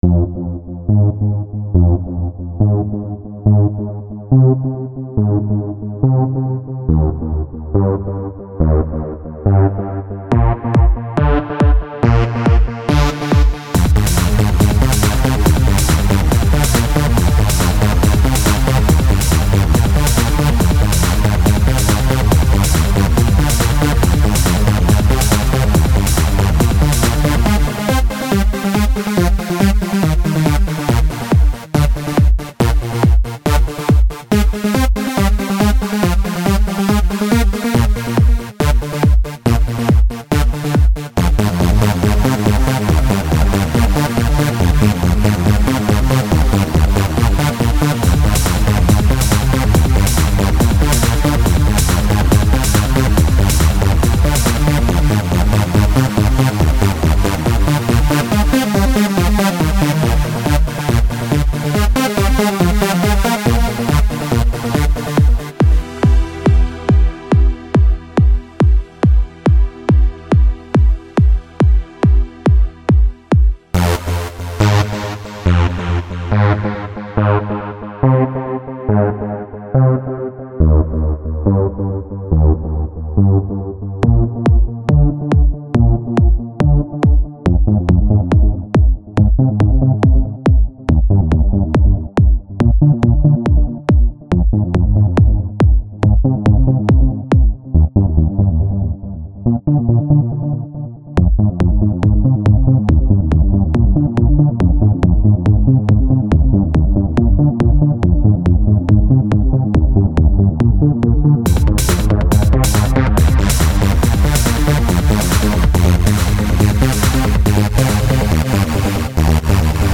Nice i like it, it has that trancy feel to it keep it up Smile
I probily shouldnt be one to judge but it seems repetitive. maybe keep it the way it is but as it grows, add more beats to it so in the end it sounds really crazy or something >.>
little too much Gverb (yes, I realize the post date, but I never saw this topic)